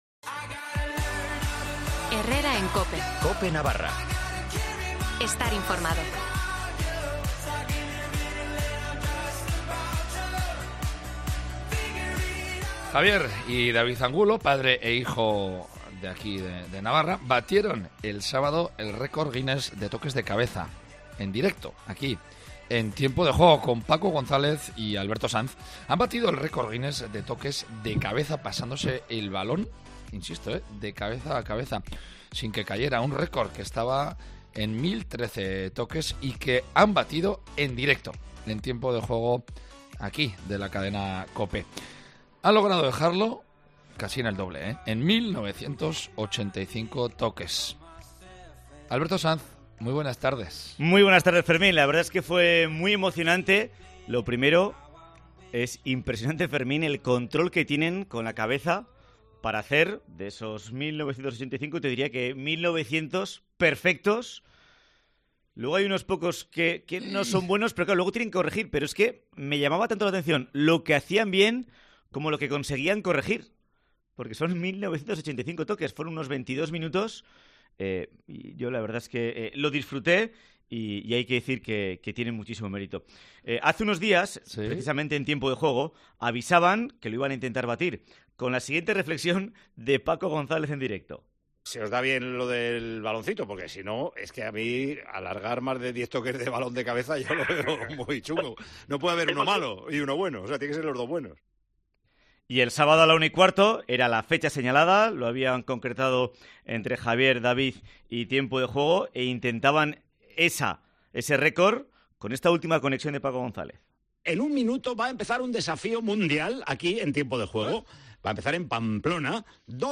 Un éxito logrado entre padre e hijo y que hizo que los aplausos se escucharan en el pabellón y en el estudio de COPE en Madrid.